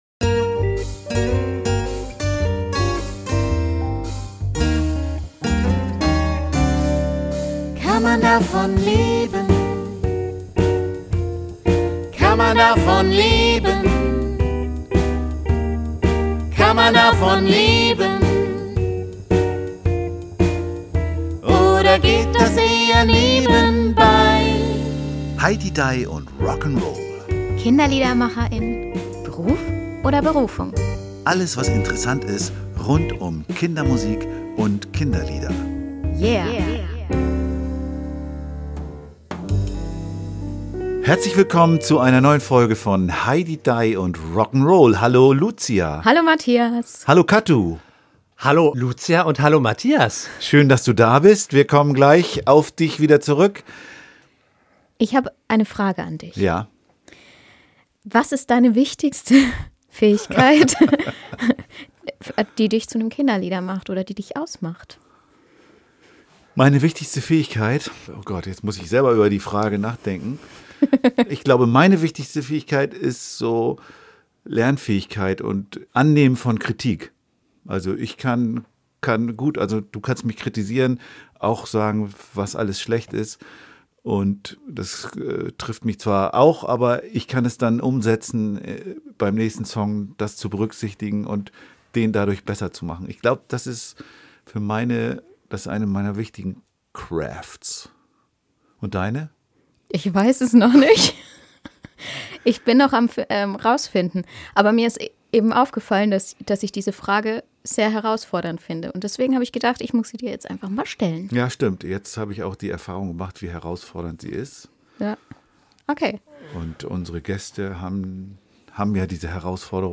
Dazu gehörte auch die professionelle Gesangsausbildung, von der er im Gespräch ebenso berichtet wie von der Gratwanderung beim Kinderlieder schreiben zwischen Funktionalität und künstlerischem Anspruch.